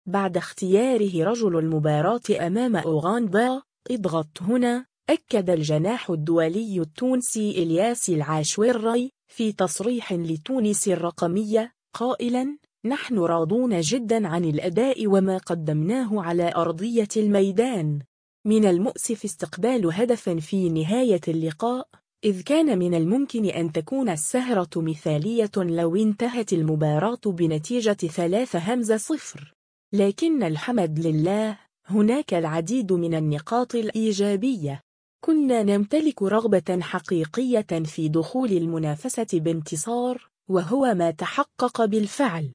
بعد اختياره رجل المباراة أمام أوغندا (اضغط هنا )، أكد الجناح الدولي التونسي إلياس العاشوري، في تصريح لـ«تونس الرقمية»، قائلاً: